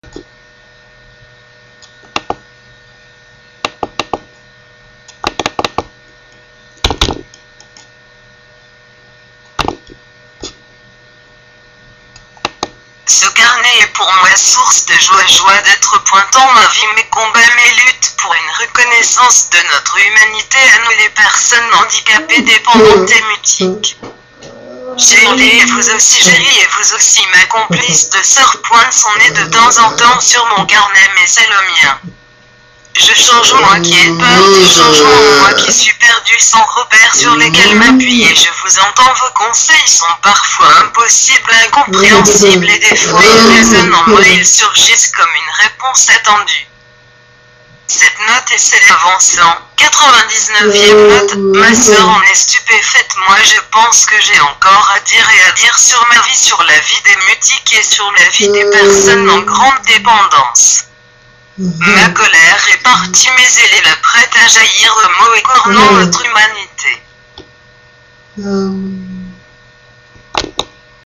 99 ème avec la voix machine